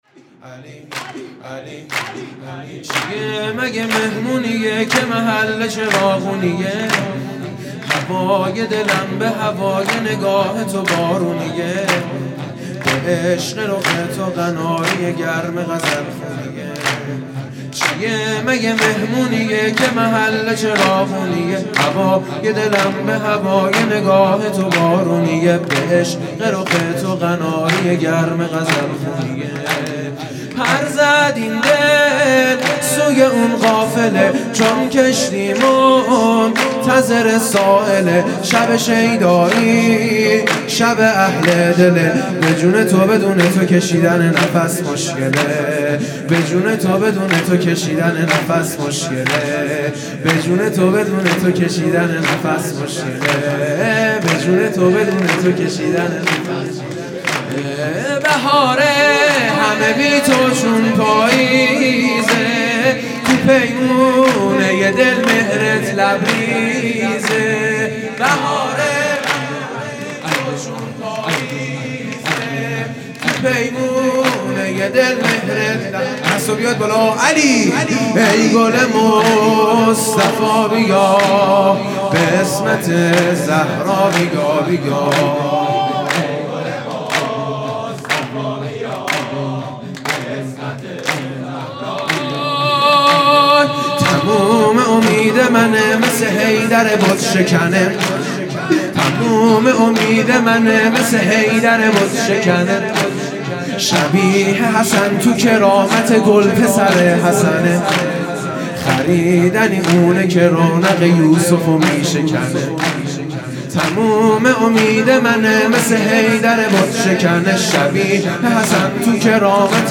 هیئت دانشجویی فاطمیون دانشگاه یزد
سرود
آغاز امامت امام زمان (عج) | ۷ آذر ۹۶